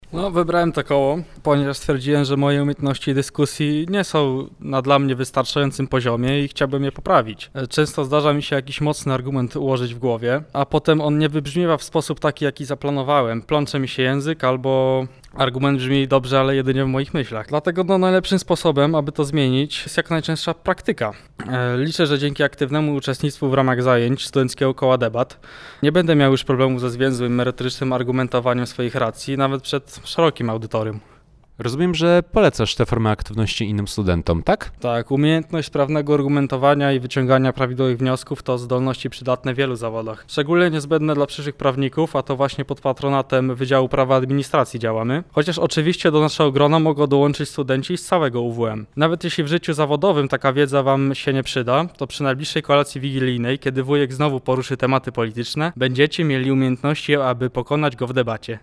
Nasz reporter rozmawiał także z jednym z nowych członków Studenckiego Koła Debat UWM